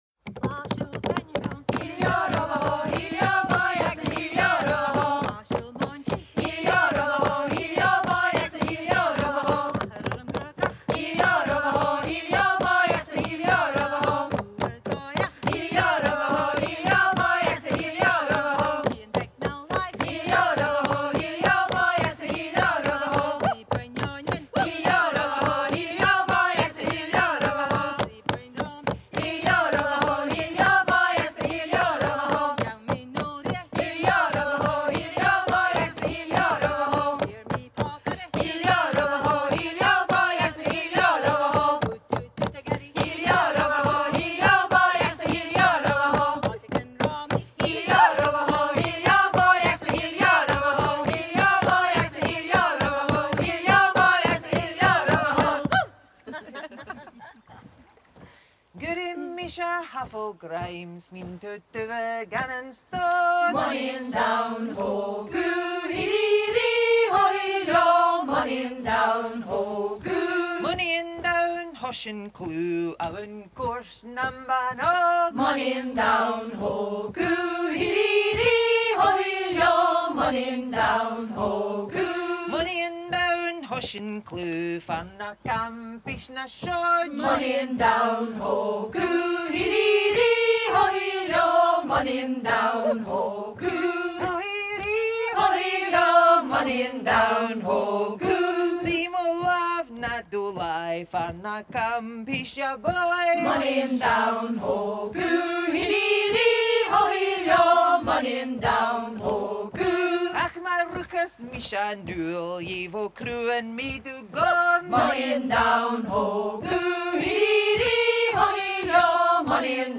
12 Wool Waulking Songs- LathaSiubhal Beinne Dhomh – Mo Nighean Donn Ho Gu.